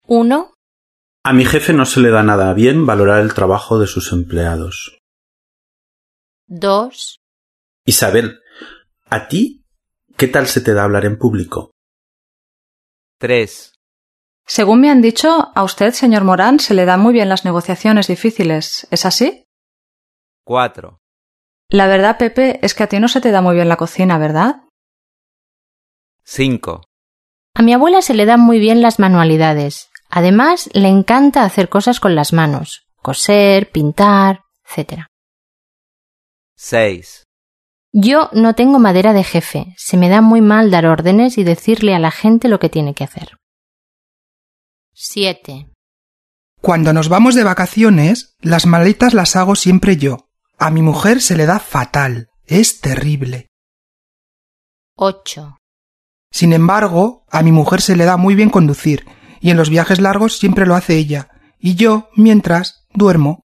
5. Escuche cómo se pronuncian las siguientes frases y repítalas.